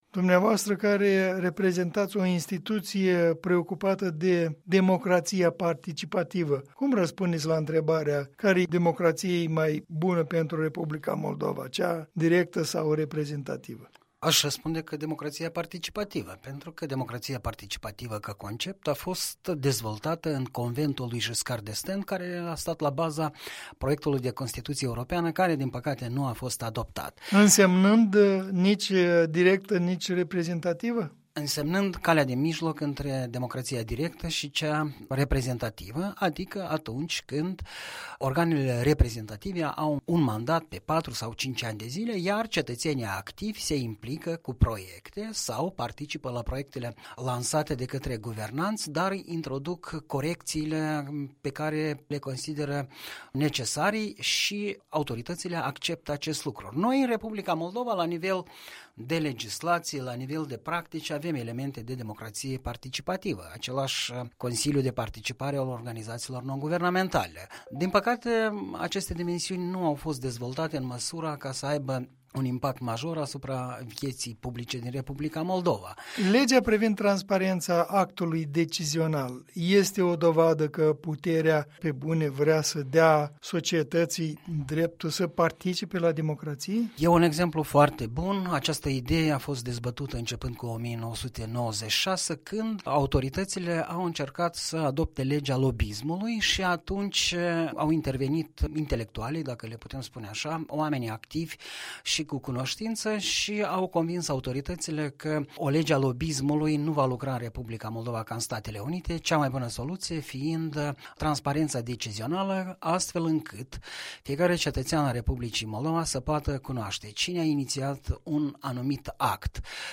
Punct de vedere săptămânal în dialog.